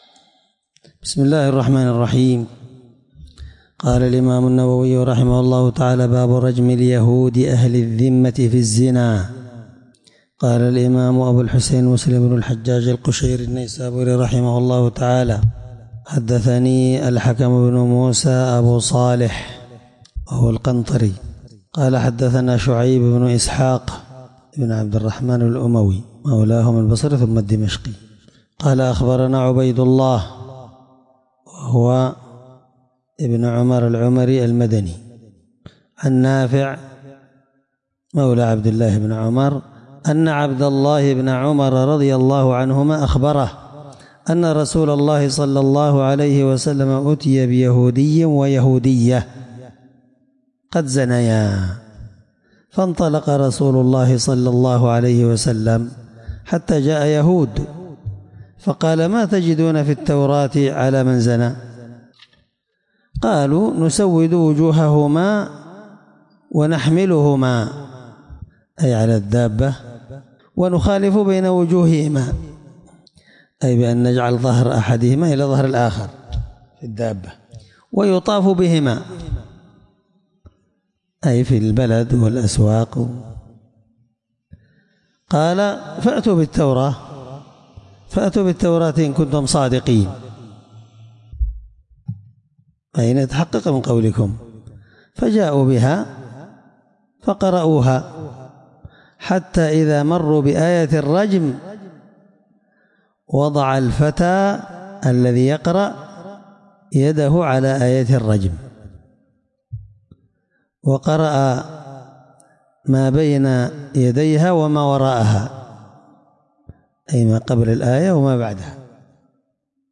الدرس11من شرح كتاب الحدود حديث رقم(1699) من صحيح مسلم